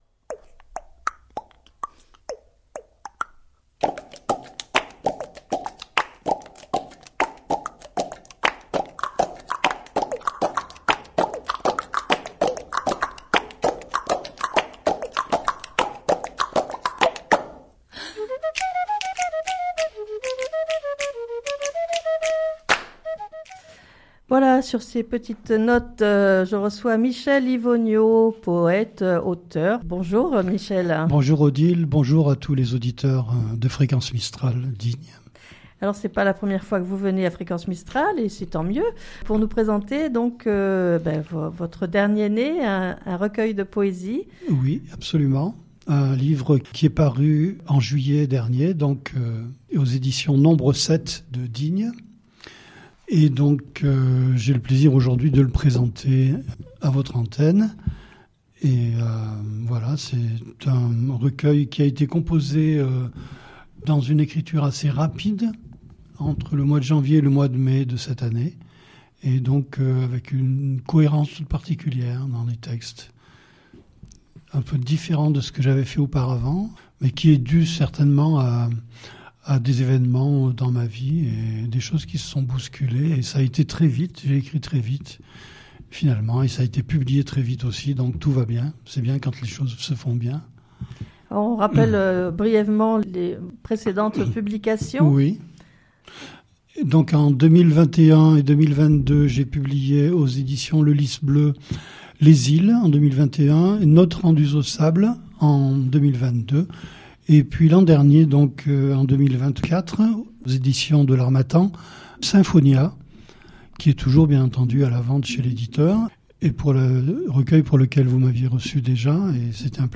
Extraits musicaux piano